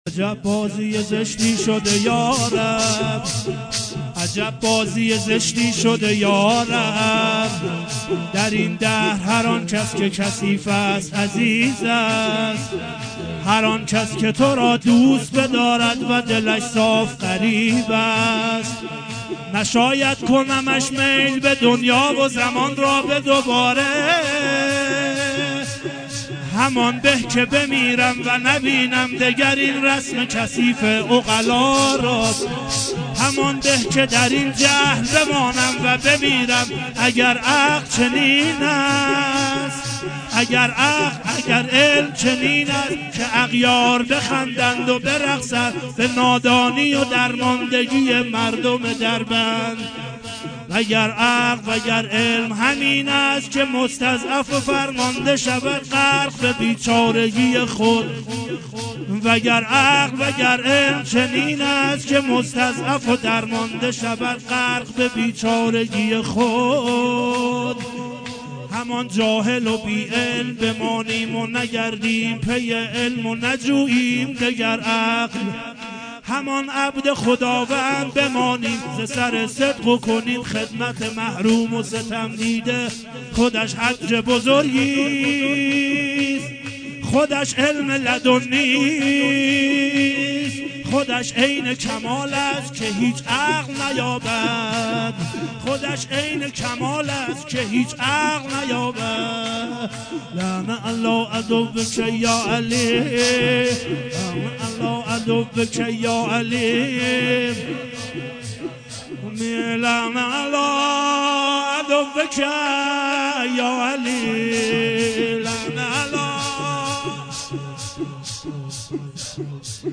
خیمه گاه - شباب الحسین (ع) - شور لعن علی عدوک یاعلی